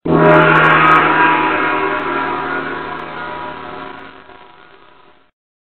gong.ogg